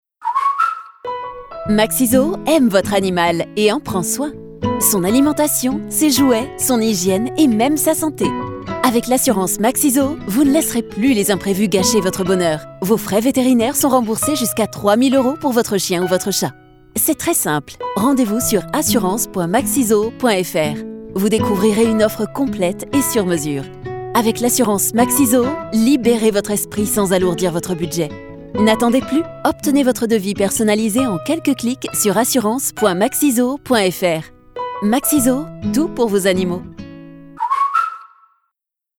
« Publicité SPEAK MAXIZOO Assurance(n° 14 18 mars 2025 18 mars 2025 18 mars 2025 18 mars 2025 speak-maxizoo-assurancen-14 SPEAK MAXIZOO Assurance(n° 14 .